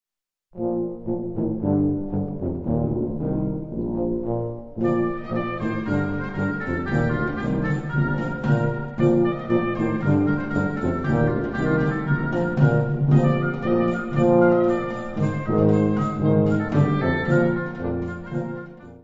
Instrumentation Ha (concert/wind band)